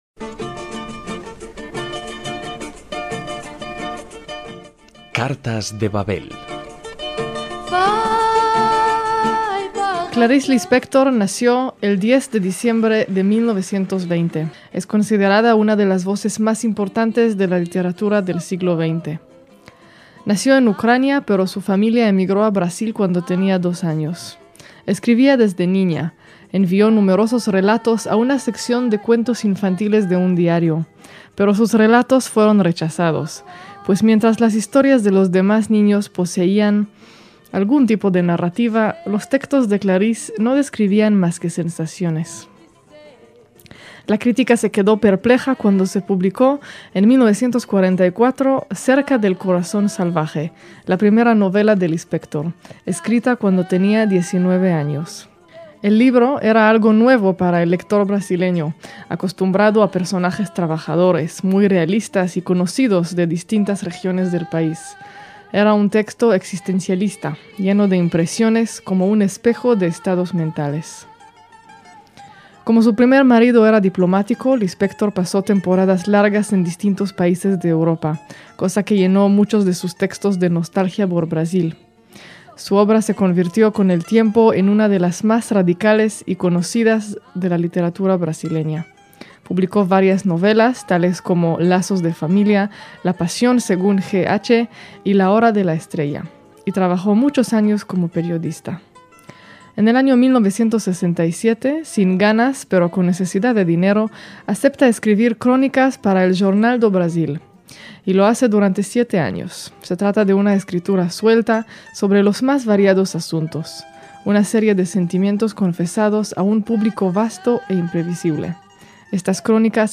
CARTAS DE BABEL - Hace exactamente 10 años, cuando se cumplían 90 años del nacimiento el 10 de diciembre de 1920 (y la muerte un 9 de diciembre de 1977) de la inclasificable escritora ucraniana-brasileña Clarice (Chaya Pinjasovna) Lispector, emitimos este espacio con la lectura de su texto "Revelación de un mundo", obra de edición póstuma (1984 en portugués, 2004 en español).